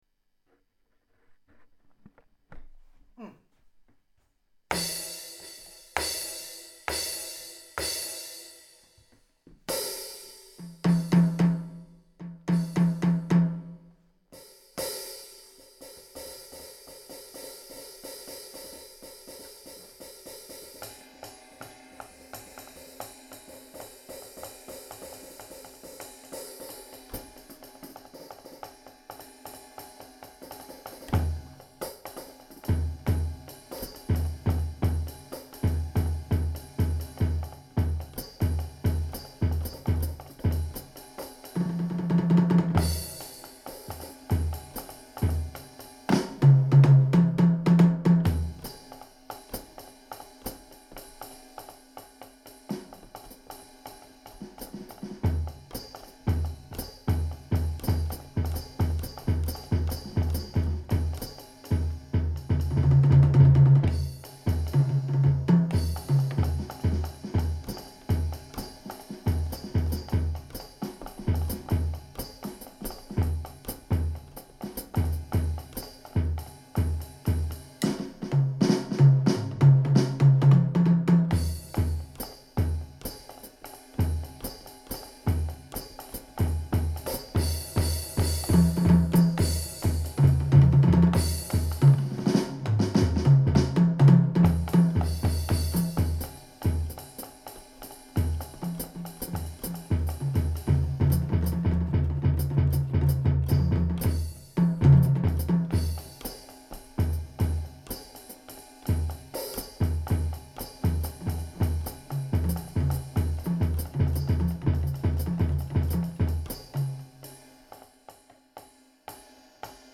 Essais percussifs